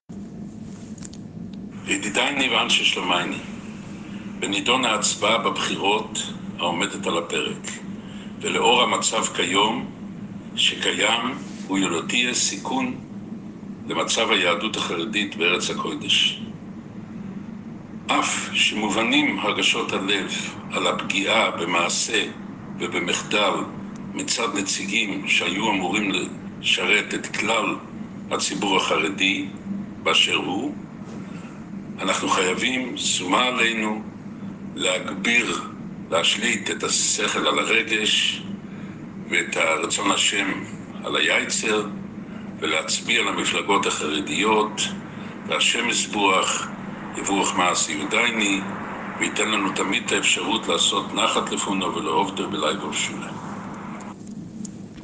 בהקלטה מקו הקהילה שהגיעה לידי 'סרוגים', ראש הישיבה מדגיש כי ההוראה ניתנה למרות האכזבה מהנציגים החרדים, שהתעלמו מהמעשים שבוצעו נגד הקהילה במהלך התקופה האחרונה.